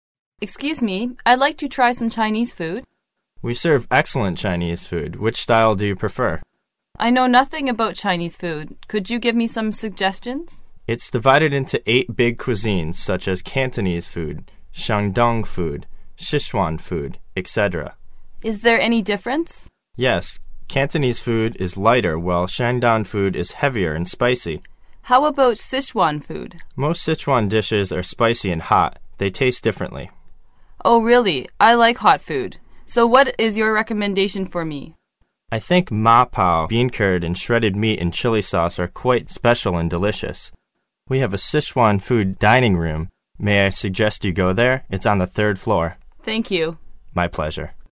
英语口语900句 02.03.对话.2.点餐 听力文件下载—在线英语听力室